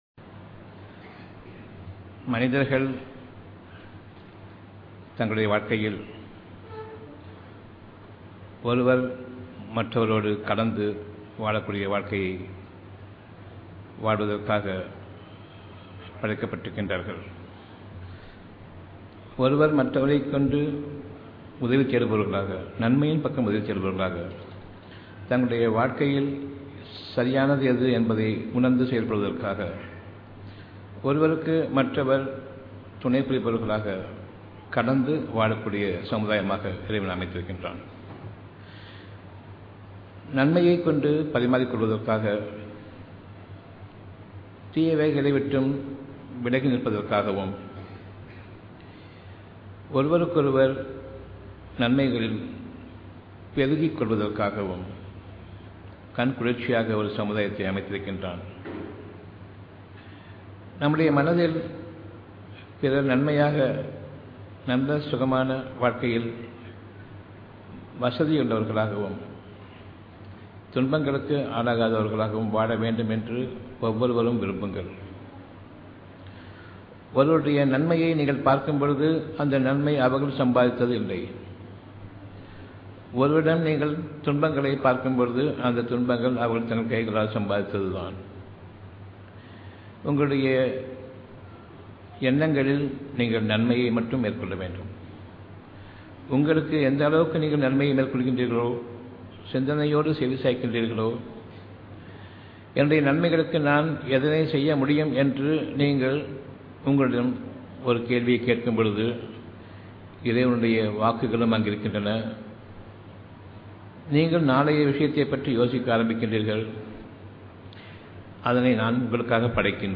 Audio Venue Sankaraiya Hall